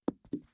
Stone Impact.wav